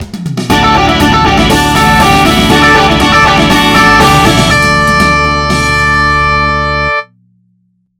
ringtone1